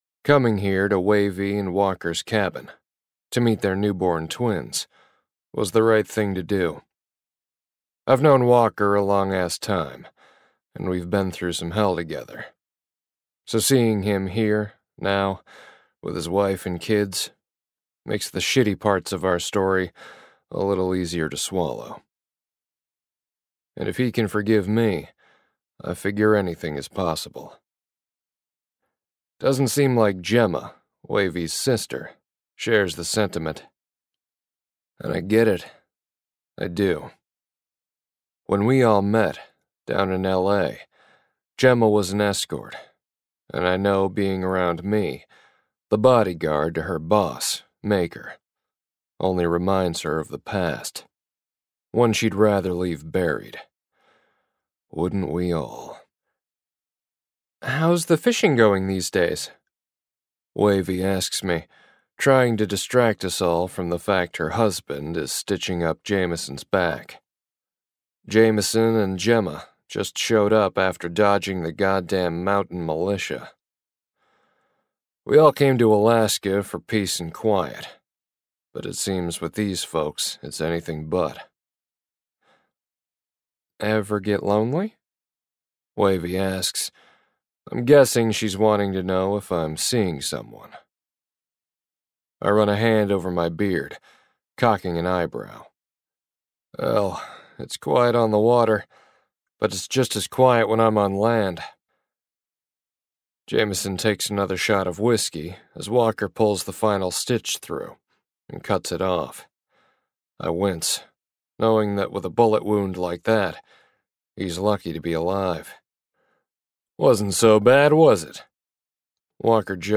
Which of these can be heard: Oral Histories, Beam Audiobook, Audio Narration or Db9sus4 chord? Beam Audiobook